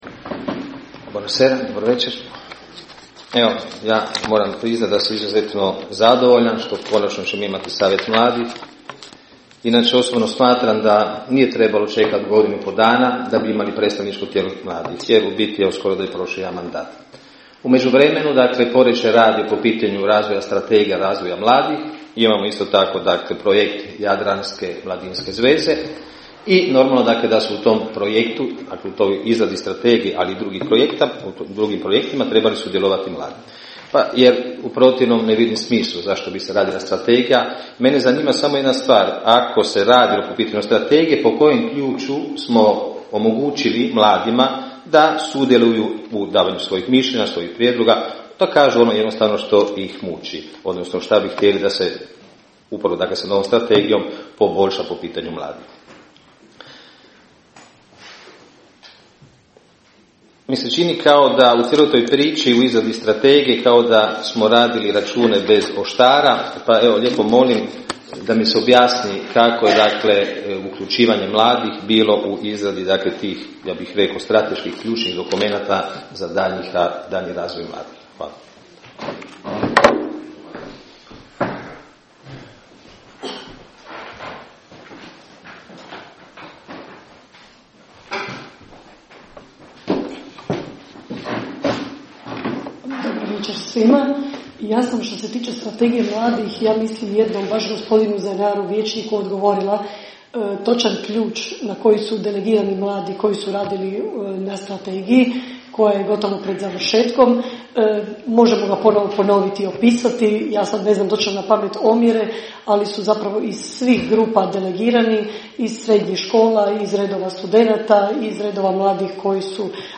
Diskusija Zennaro - Basanić Čuš